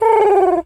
pigeon_call_calm_09.wav